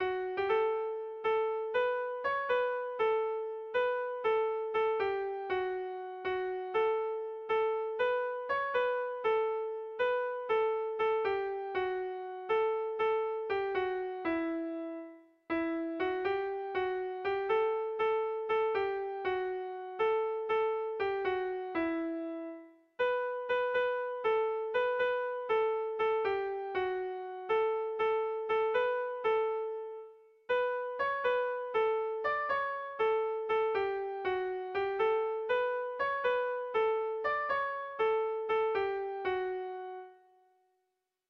Hauxe da ikazketako B - Air de bertsos - BDB.
Sentimenduzkoa
Zortzi puntuko berezia
ABD..